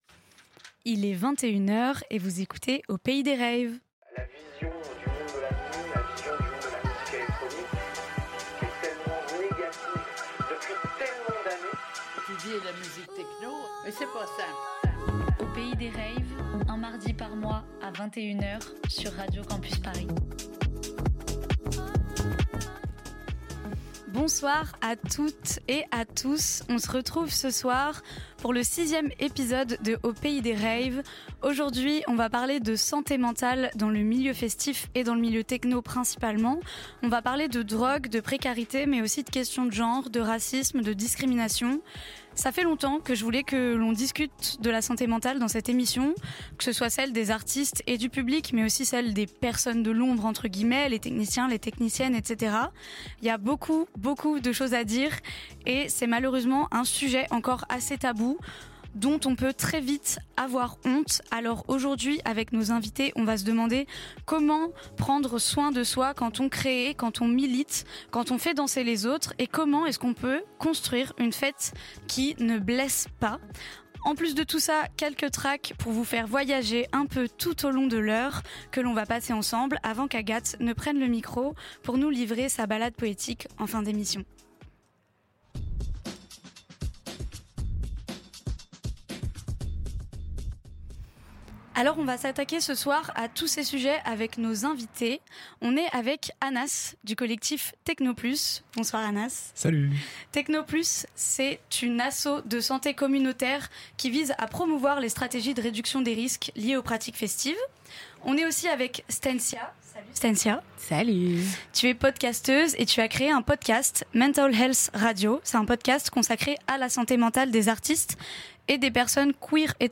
Retrouvez le sixième épisode, en direct, de l'émission Aux Pays Des Raves !